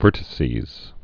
(vûrtĭ-sēz)